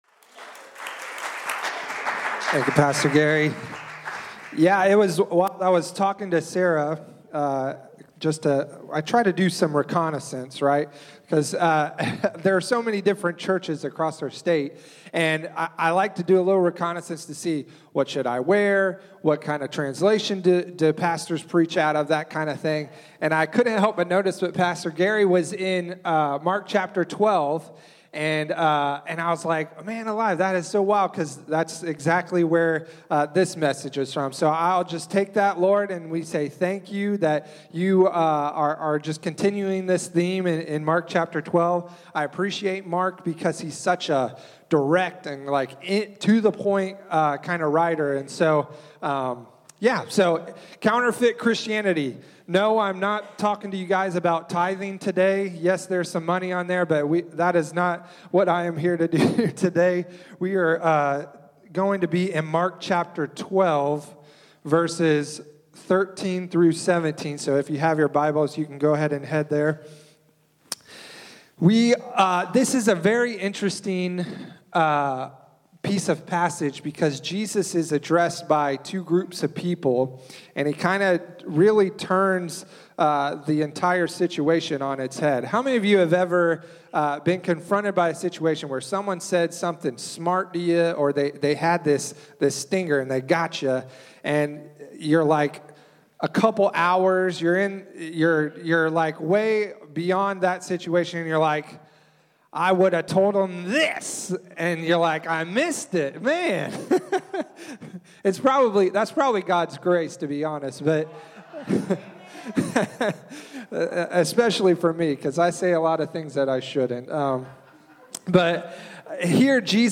Special Messages